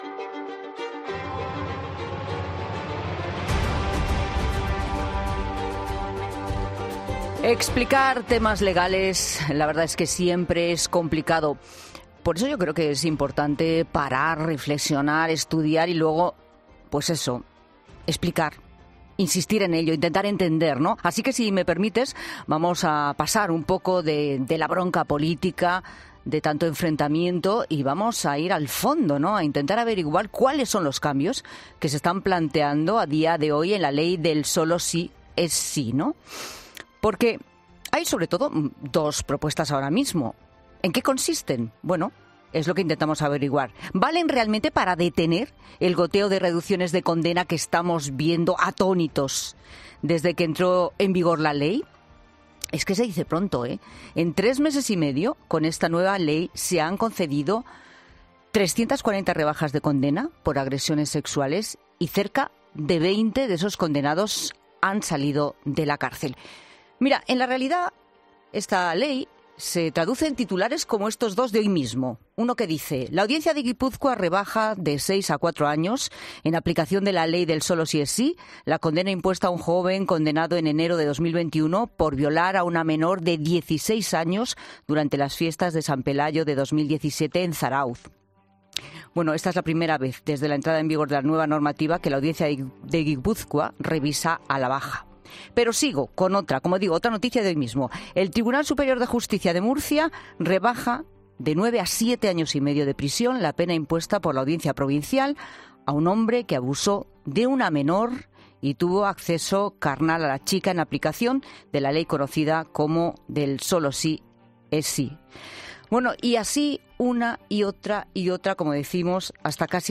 escritor y columnista.
abogada penalista y criminóloga .